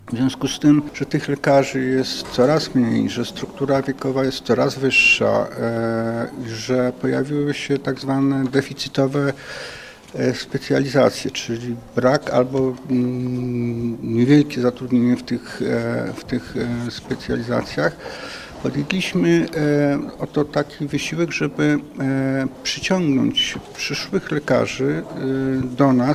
31 studentów medycyny, którzy swoją zawodową przyszłość zwiążą ze szpitalami z województwa świętokrzyskiego, otrzymało stypendia fundowane przez Zarząd Województwa. Mówi członek zarządu Marek Bogusławski.